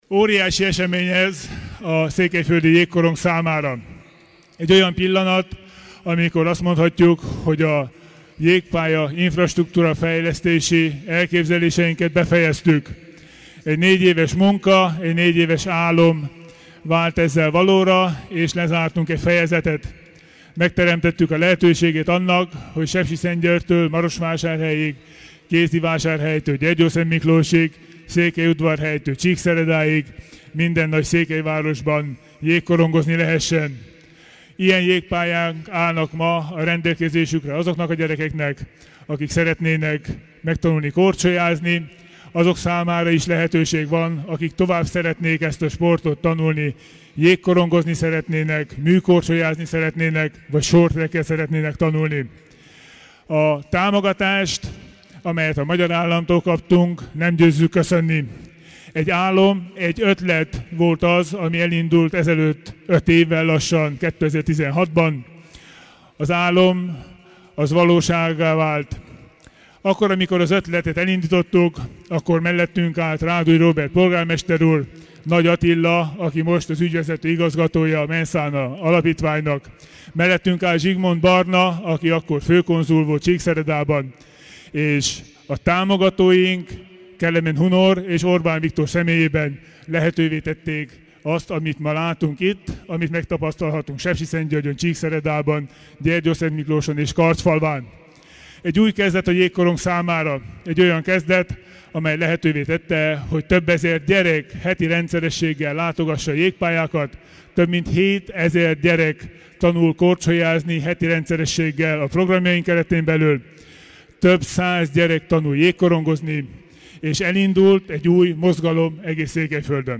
Az ünnepséget, házigazdaként Tánczos Barna környezetvédelmi miniszter nyitotta meg, aki a SZJA-t működtető Mens Sana alapítvány elnöke is: